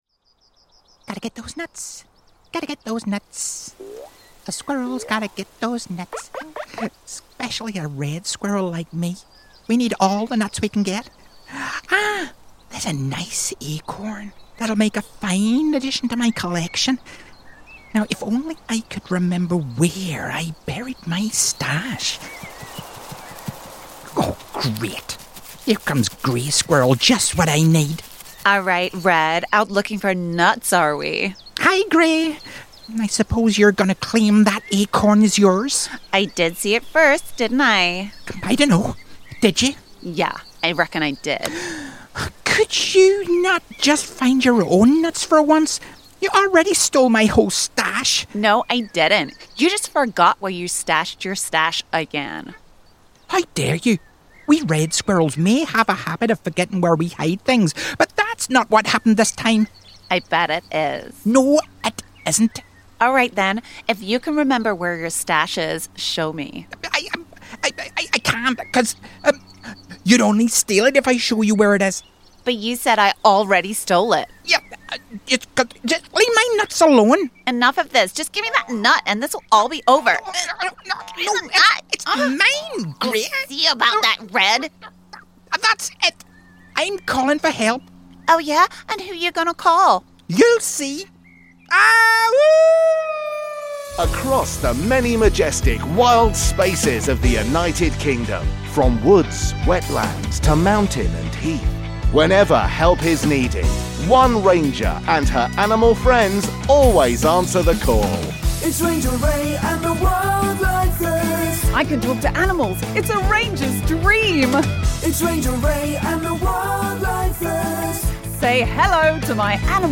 All other characters played by members of the ensemble.